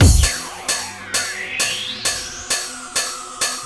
NoisyPercLoop-44S.wav